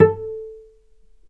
healing-soundscapes/Sound Banks/HSS_OP_Pack/Strings/cello/pizz/vc_pz-A4-mf.AIF at bf8b0d83acd083cad68aa8590bc4568aa0baec05
vc_pz-A4-mf.AIF